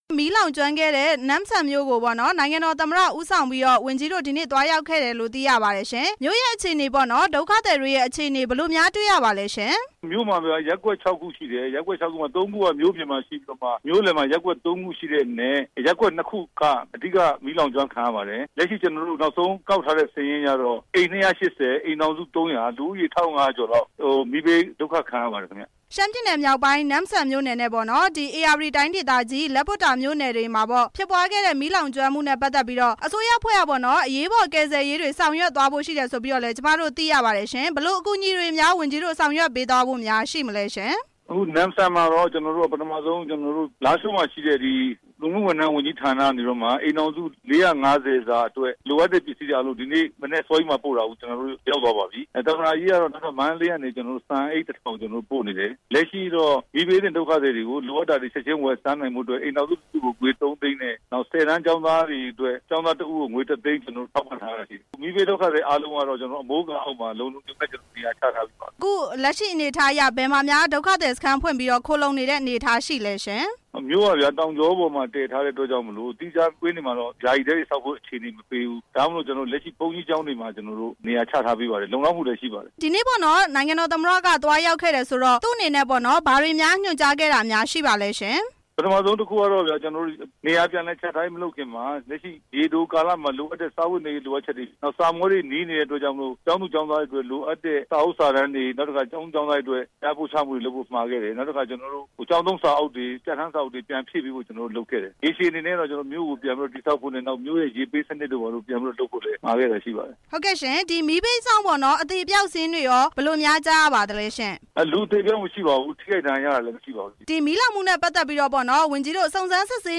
ဝန်ကြီး ဦးရဲထွဋ် ကို မေးမြန်းချက်